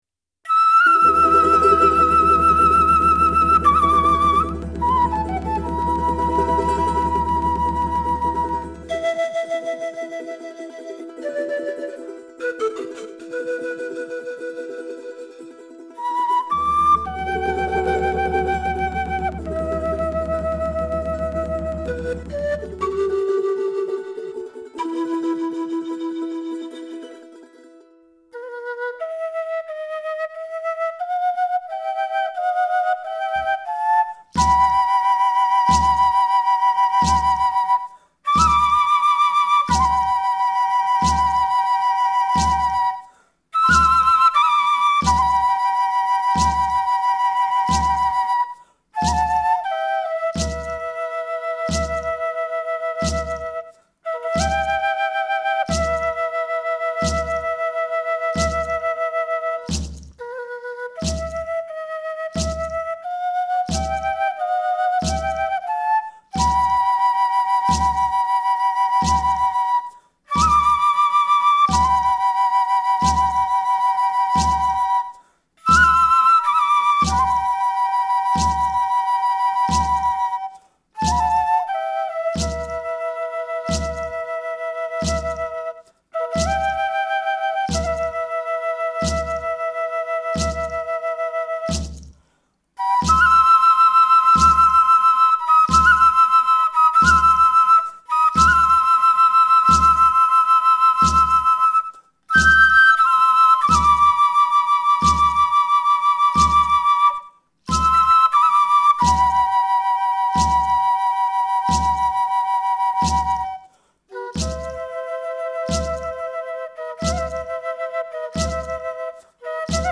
基準となるリズムを聴きながら、それぞれの楽器（ケーナ、サンポーニャ、チャランゴ、ギターなど）を演奏し録音します。
別々に録音した楽器やパソコンで作ったパーカッションをミックスして完成します。
スタジオは鍼灸院の待合室です。
日本でフォルクローレと言えば、この曲ですね。